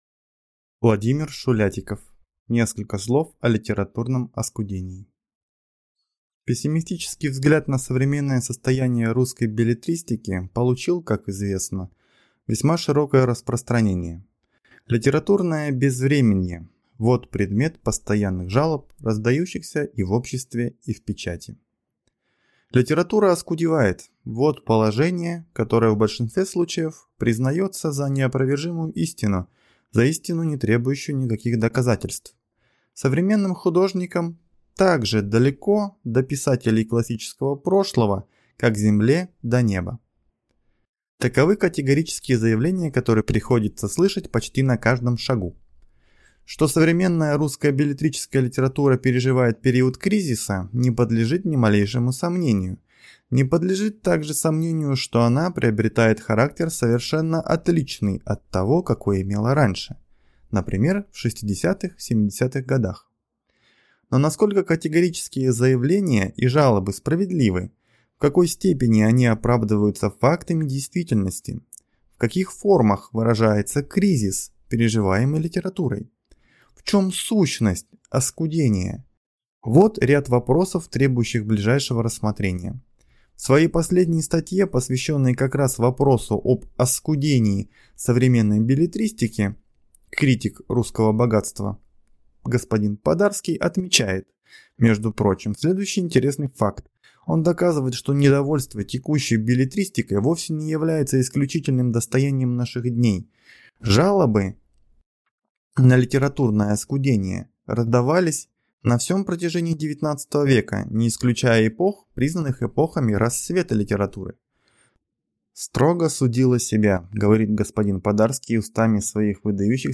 Аудиокнига Несколько слов о литературном «оскудении» | Библиотека аудиокниг